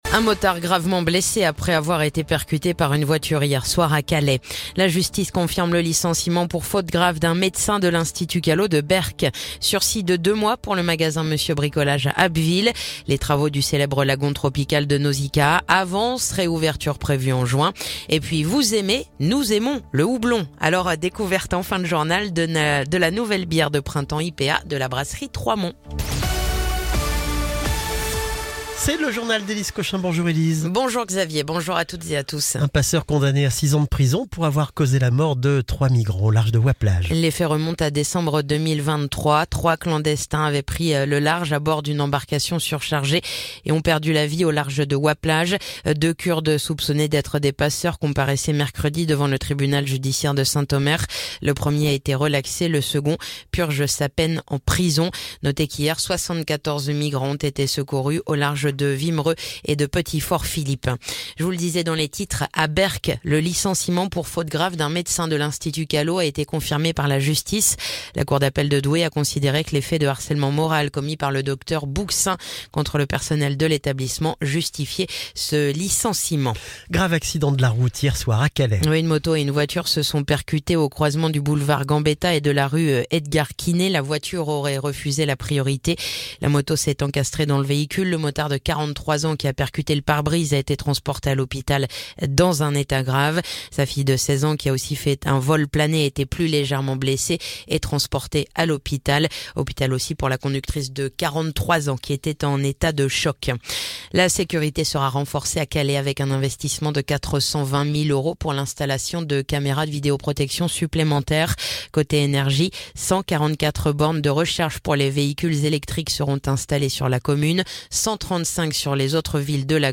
Le journal du vendredi 28 mars